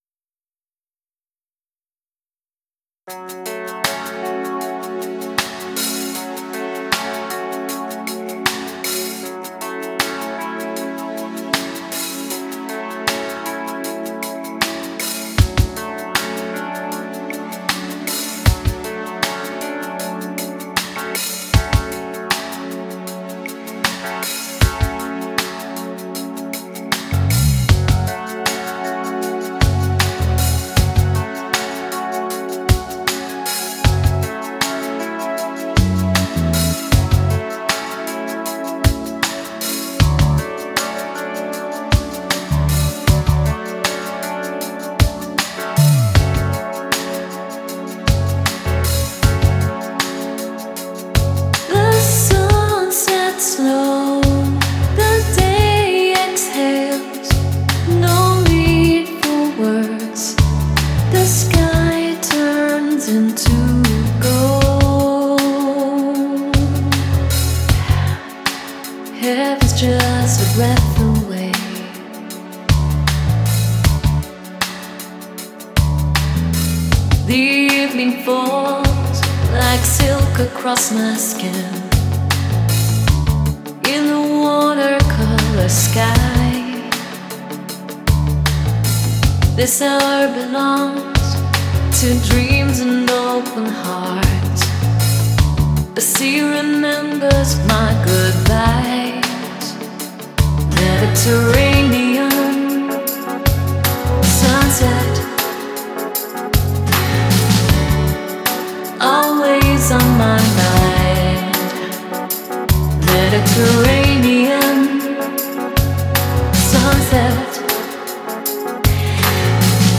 Mein allerneuestes Stück ist ein Chillout-Track vom Juli 2025. Die Besonderheit hier ist, dass ich das erste Mal den Gesang mittels eines KI-Plugins (Dreamtonics Synthesizer V Studio 2 Pro) erstellt habe.